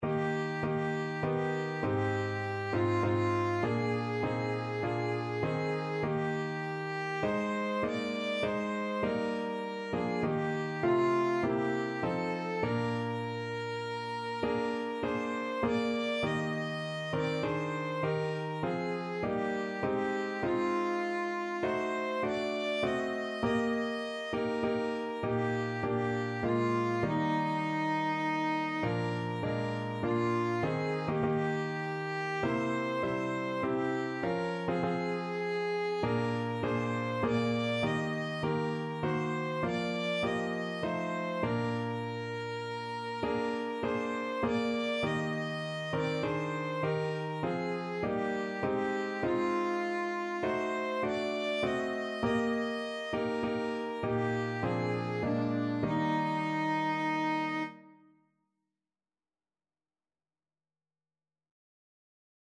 3/4 (View more 3/4 Music)
Classical (View more Classical Violin Music)